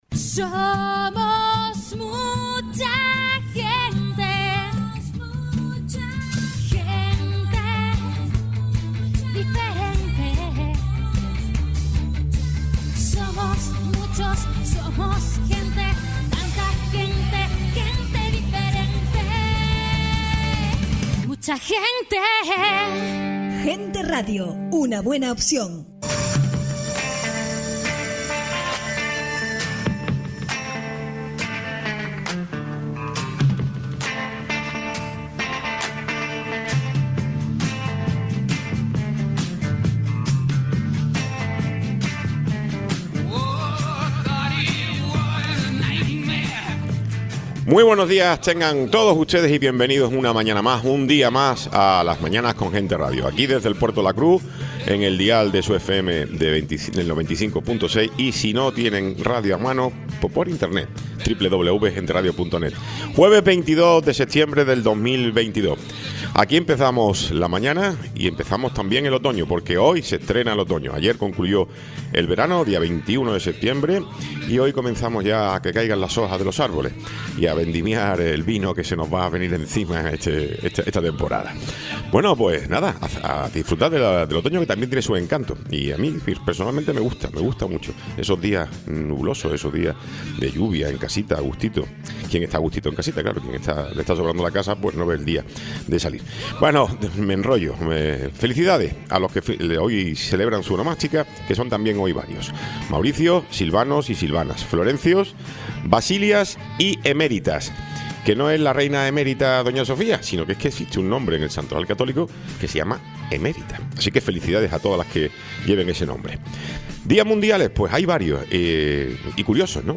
Tiempo de entrevista
Tiempo de entrevista con Carlos Alonso, portavoz CC en el Cabildo de Tenerife